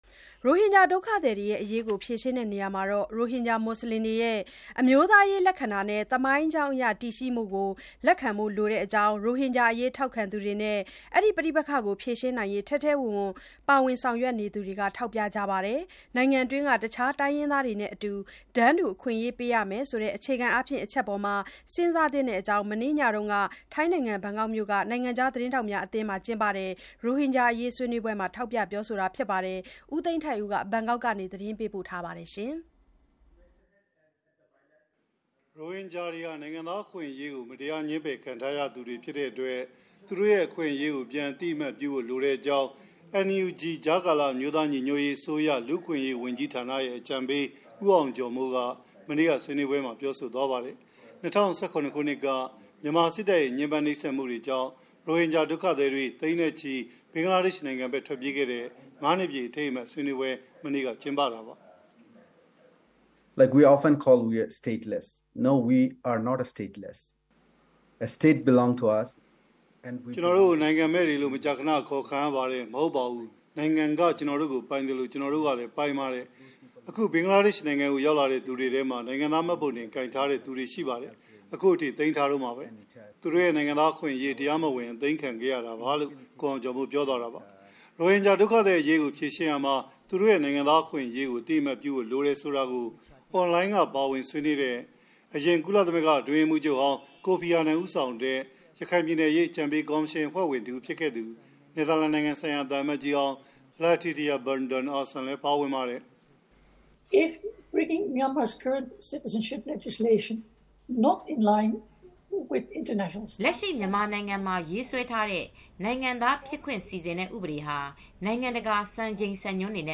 ရိုဟင်ဂျာတည်ရှိမှု လက်ခံရေး ပြဿနာရဲ့ အခြေခံဖြစ်နေ (FCCT ဆွေးနွေးပွဲ).mp3